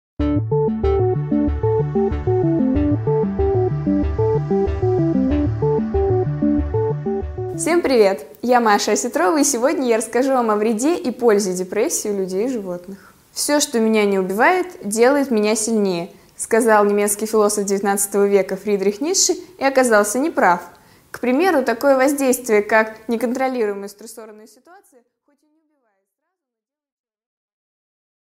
Аудиокнига 5 минут О депрессии у людей и животных | Библиотека аудиокниг